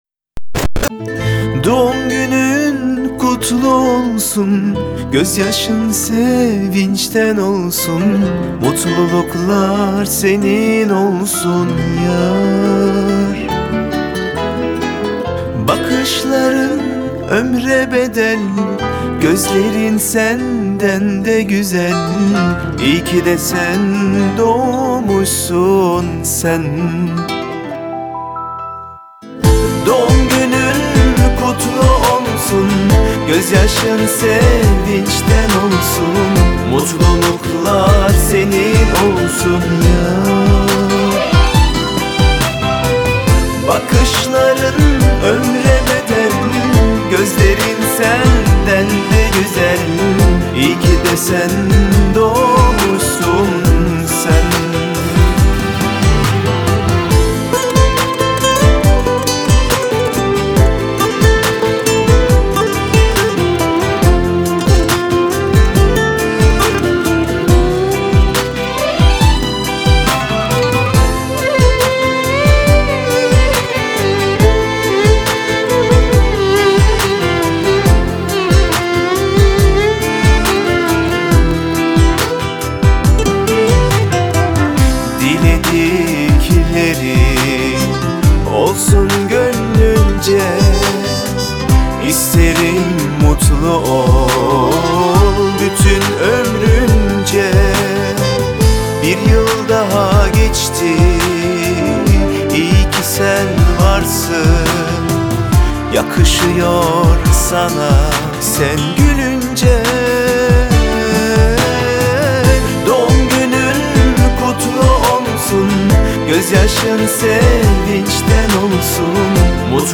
آهنگ ترکیه ای استانبولی